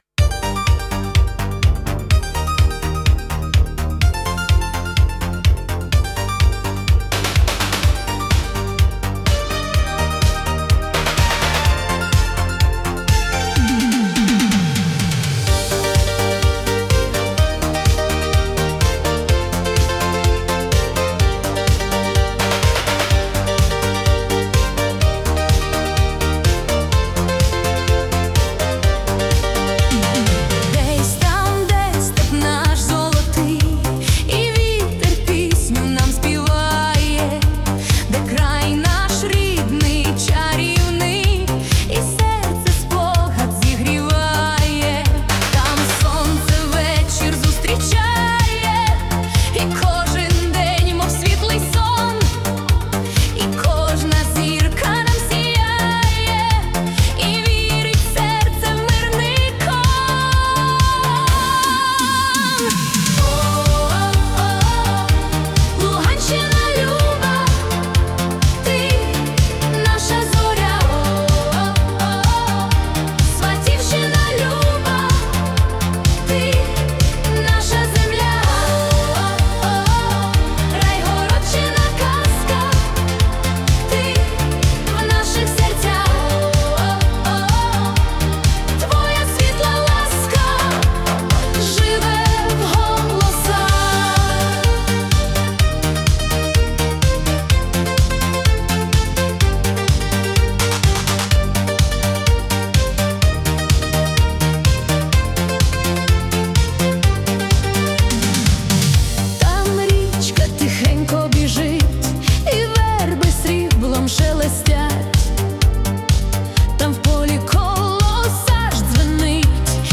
🎵 Жанр: Italo Disco (Nature Theme)